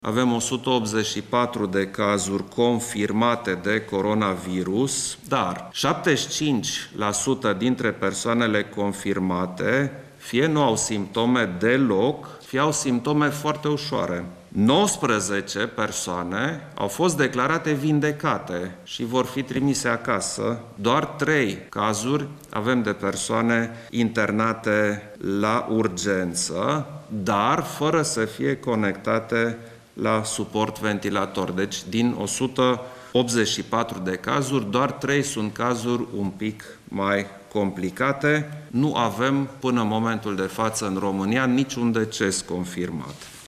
În videoconferința cu reprezentanții Guvernului, șeful statului le-a cerut acestora să precizeze impactul asupra populaţiei și să facă o evaluare pe termen foarte scurt şi pe durata celor 30 de zile cât durează starea de urgență.
Președintele Klaus Iohannis le-a prezentat premierului şi miniştrilor o statistică referitoare la cele 184 de cazuri confirmate până acum de coronavirus în România: